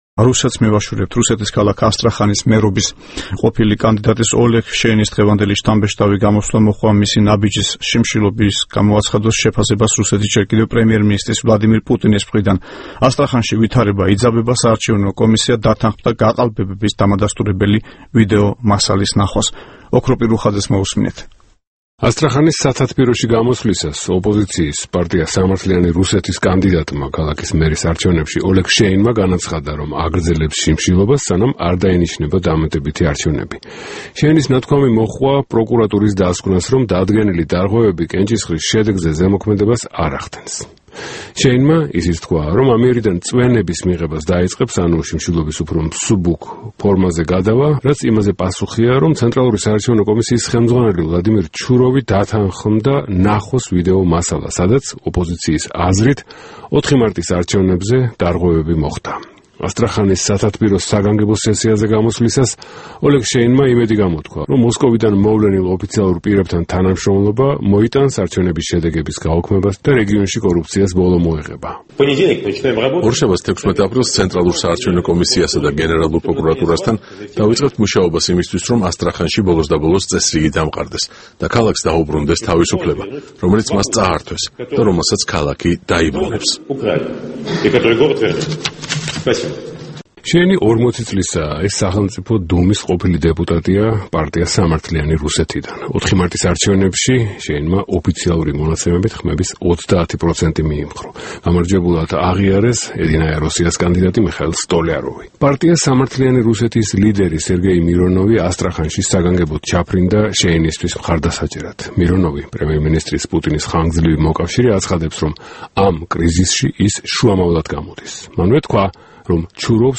მერობის მოშიმშილე კანდიდატის შთამბეჭდავი გამოსვლა სათათბიროში